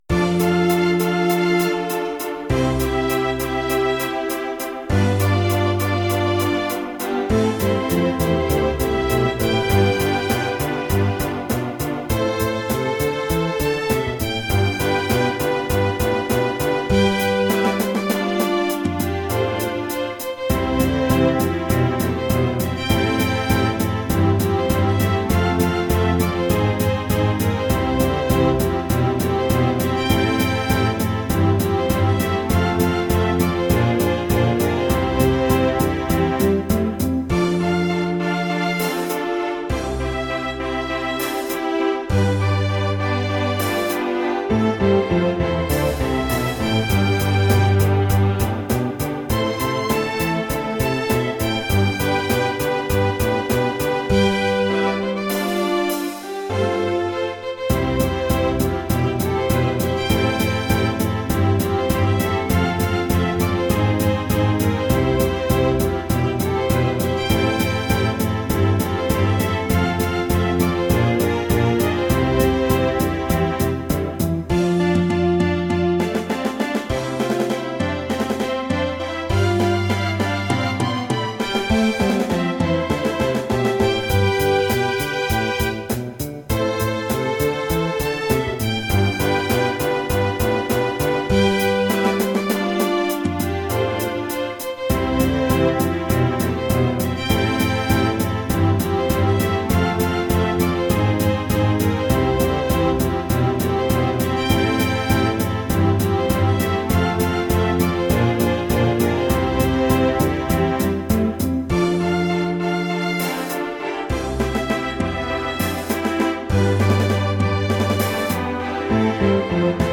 Cello Double Bass Tympani
Drums Percussion Tuned Percussion
Piano Harpsichord Hammond Organ
Synthesizer Classical Guitar Electric Guitar